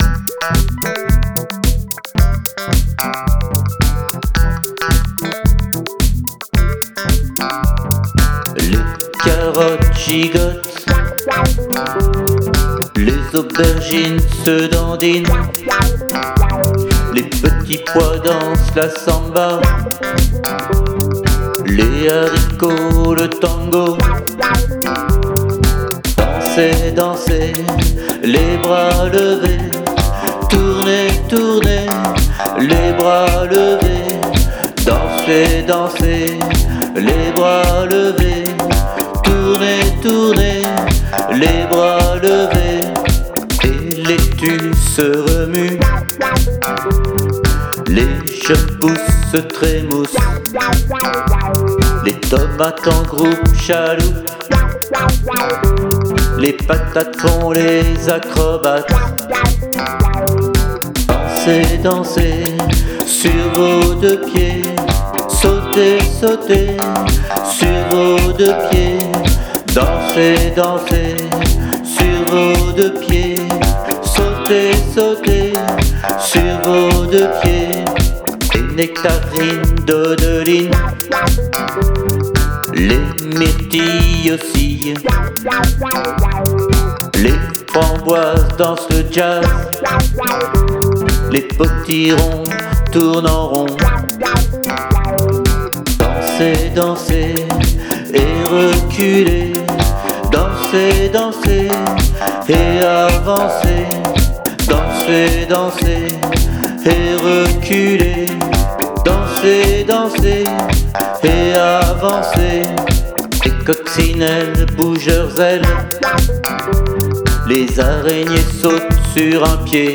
Spectacle "bataille dans le jardin" créé en juin 2014 à l'école maternelle de la Plaine à Bassens
Chanson finale